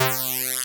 Electricity1.wav